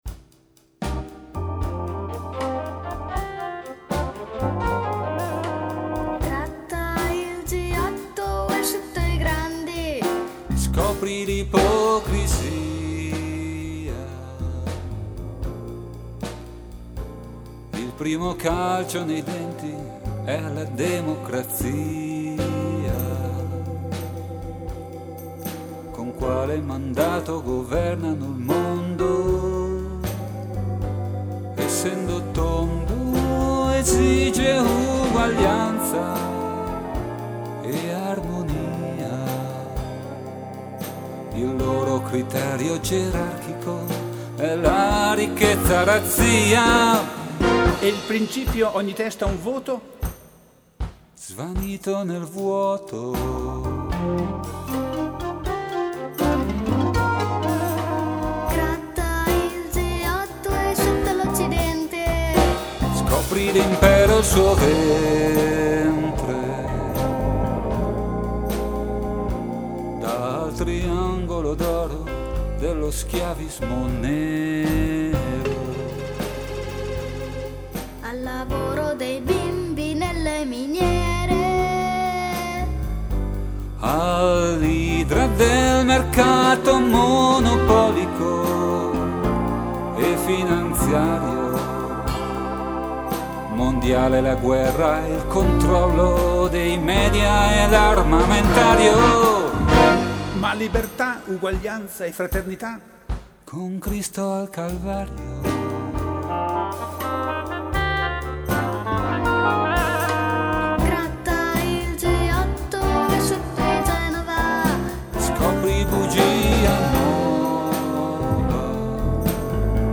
batteria